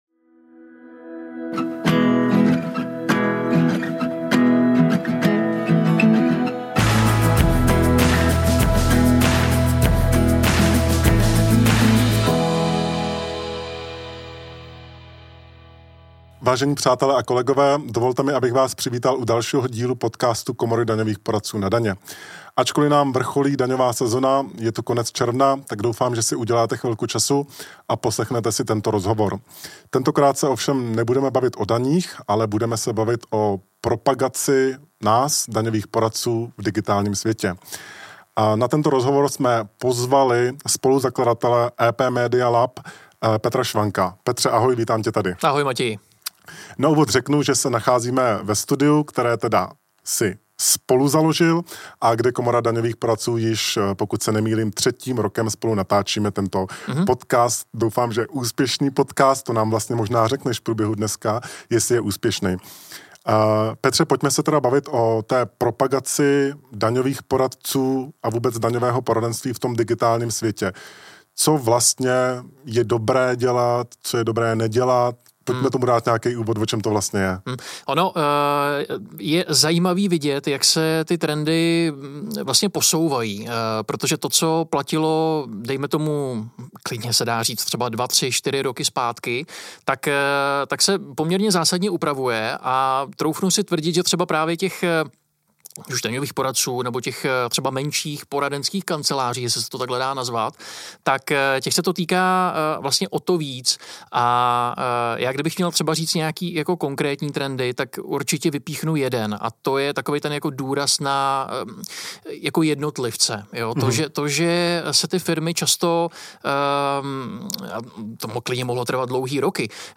🎧 Poslechněte si rozhovor a zjistěte, jak může i malá poradenská firma efektivně oslovit své publikum v digitálním světě – bez toho, aby ztratila autenticitu nebo rozpočet.